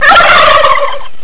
gobble01.wav